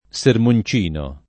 sermoncino [ S ermon ©& no ] s. m.